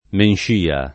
Menscia [ menš & a ]